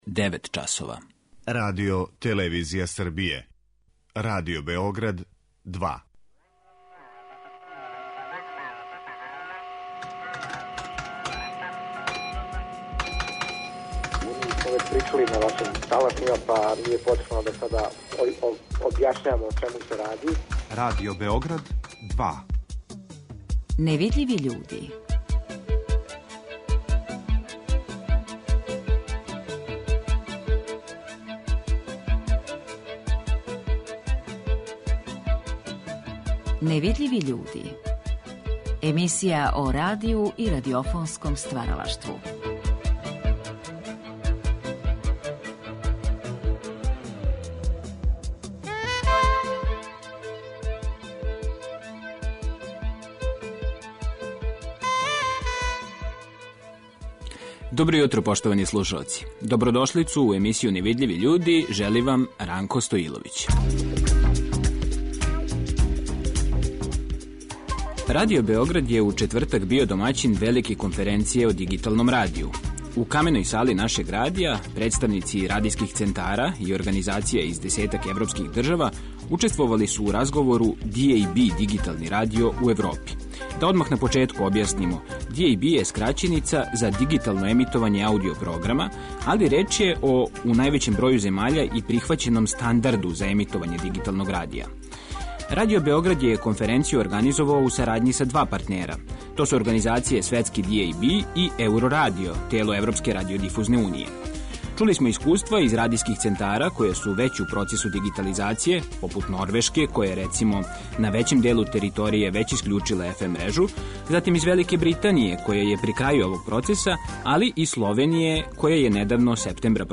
Ови разговори вођени су још 1974. године, у првој сезони емисије „Гост Другог програма".